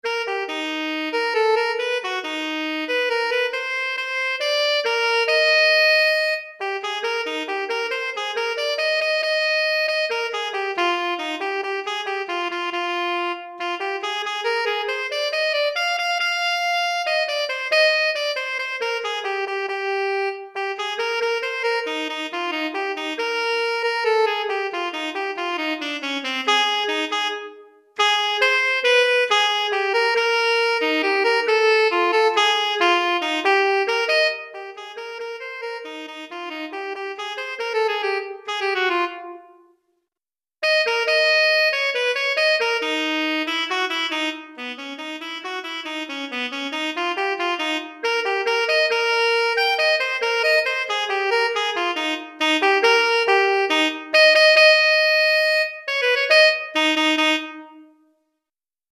Saxophone Alto Solo